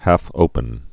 (hăfōpən, häf-)